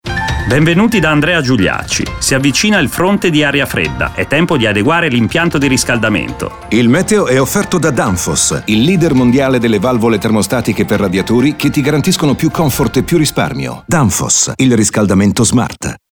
La campagna è in onda in questi giorni su RTL e sarà trasmessa fino al 24 settembre.